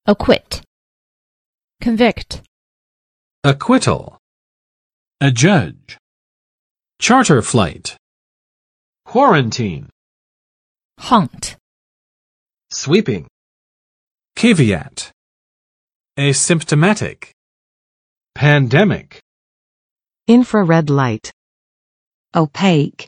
[əˋkwɪt] v. 宣告……无罪，无罪释放